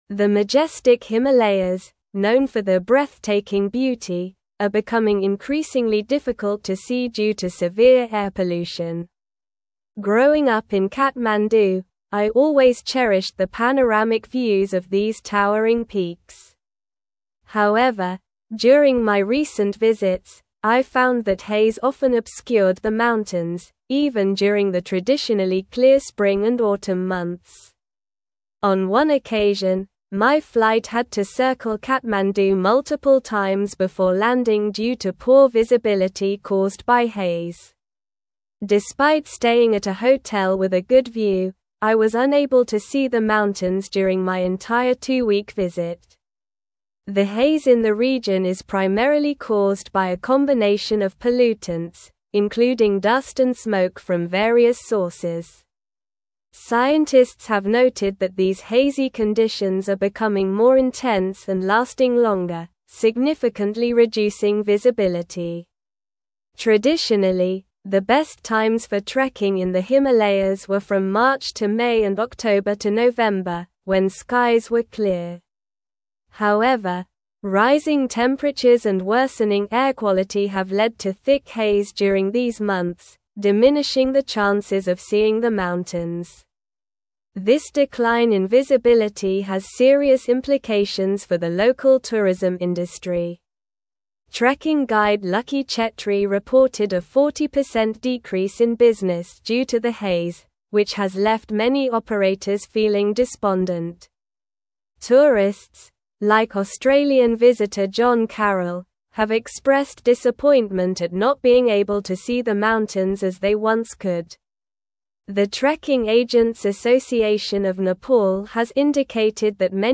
Slow
English-Newsroom-Advanced-SLOW-Reading-Himalayan-Views-Obscured-by-Rising-Air-Pollution.mp3